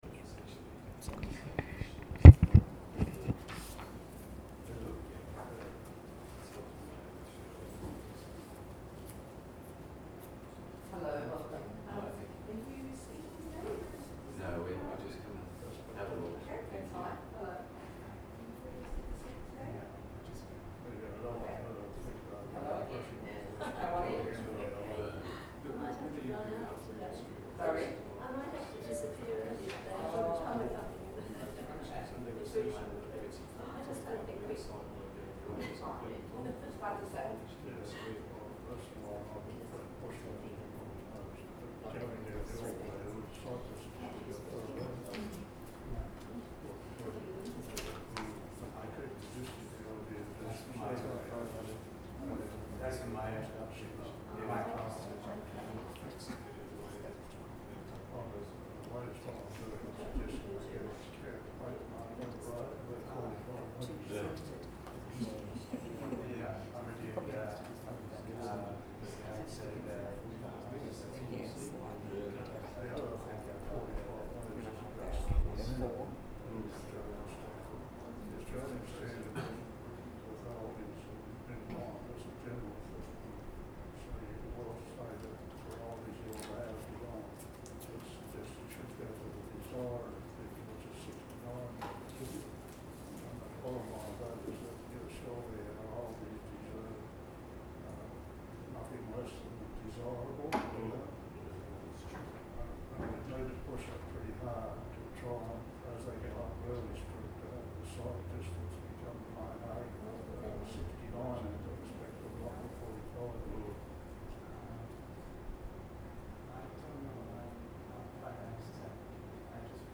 Recording-of-Public-Meeting-KLPP-Monday-19-August-2019.mp3